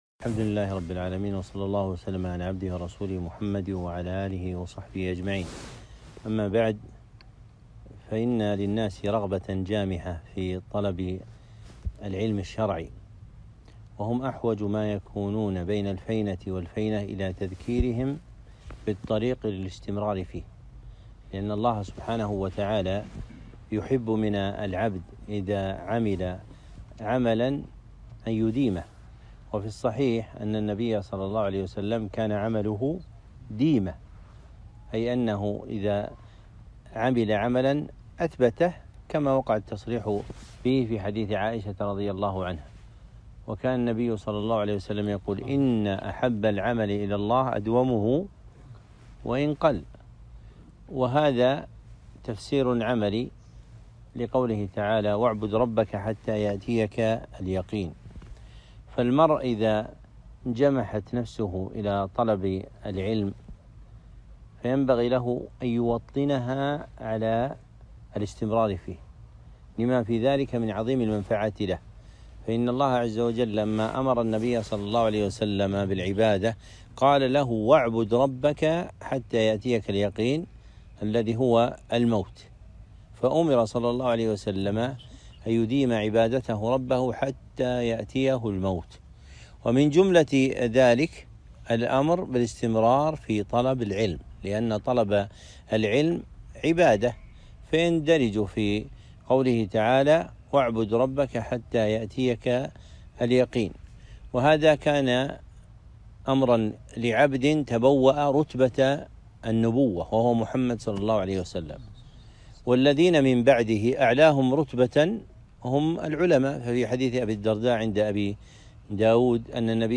كلمة - أصول مقوية للاستمرار في طلب العلم